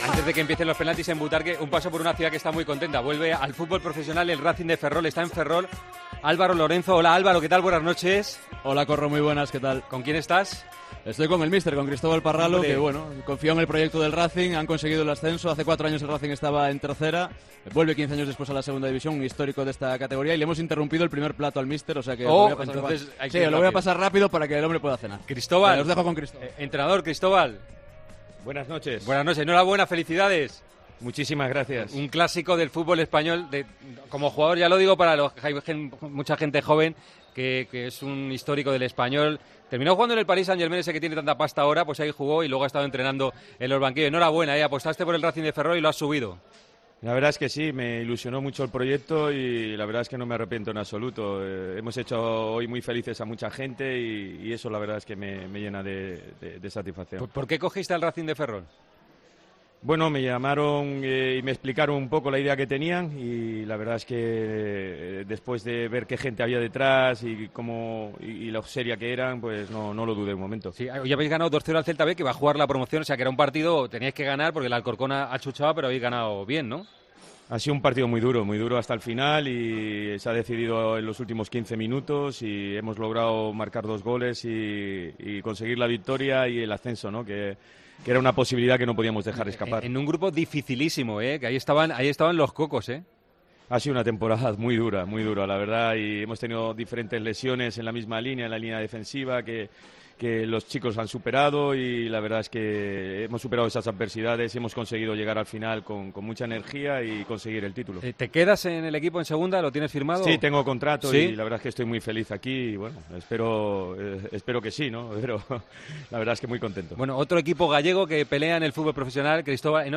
AUDIO: El entrenador del Racing de Ferrol se pasó por los micrófonos de Tiempo de Juego horas después de conseguir el ascenso a LaLiga Smartbank.